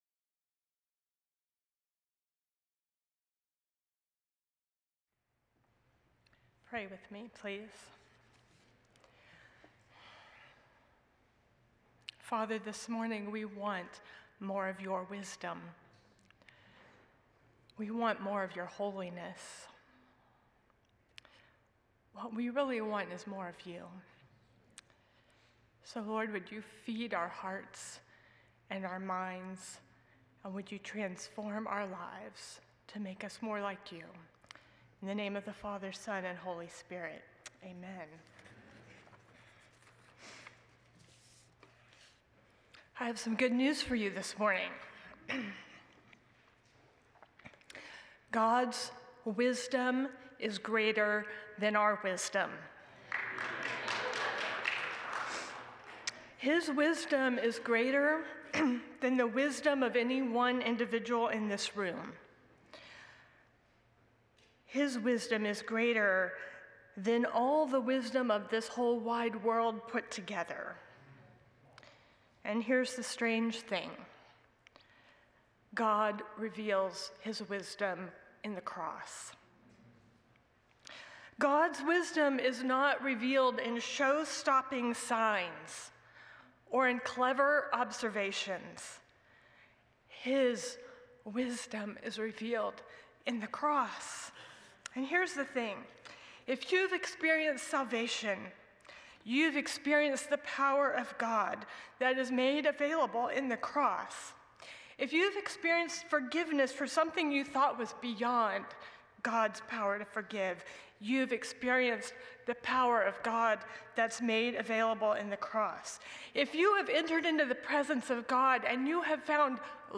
The following service took place on Tuesday, February 24, 2026.
Sermon